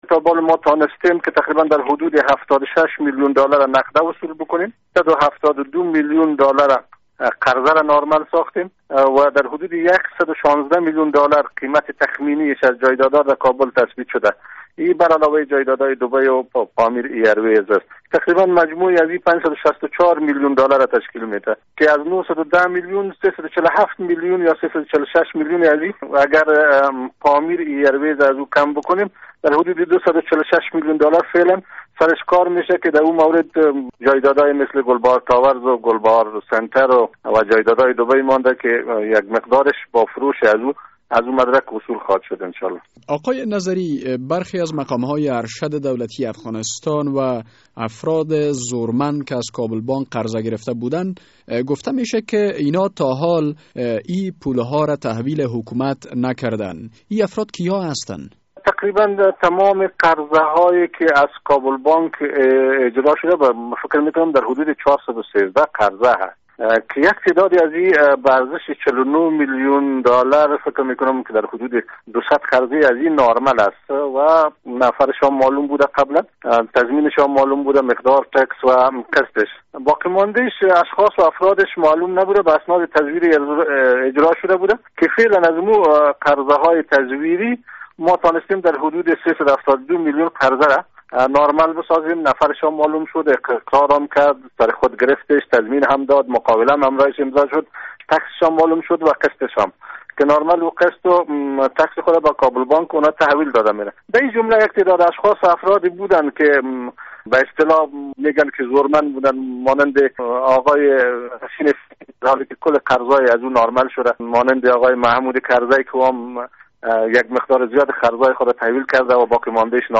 مصاحبه در مورد درخواست استرداد عبدالقدیرت فطرت